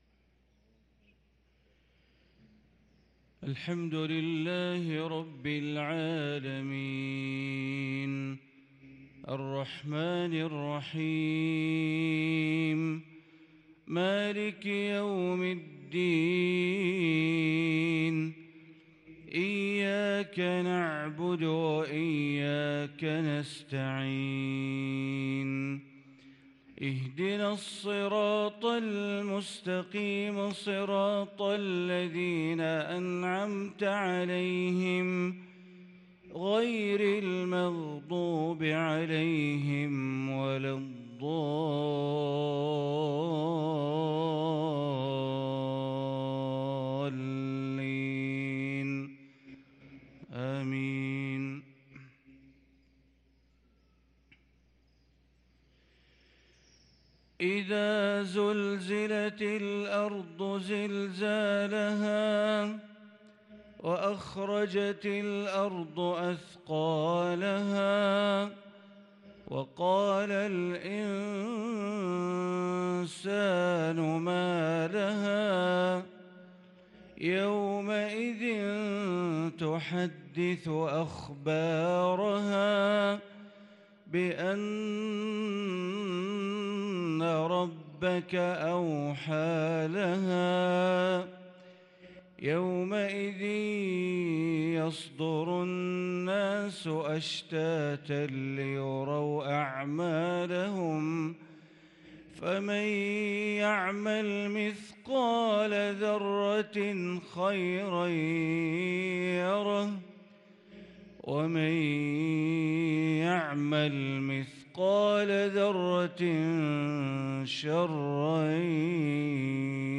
صلاة المغرب للقارئ بندر بليلة 4 شوال 1443 هـ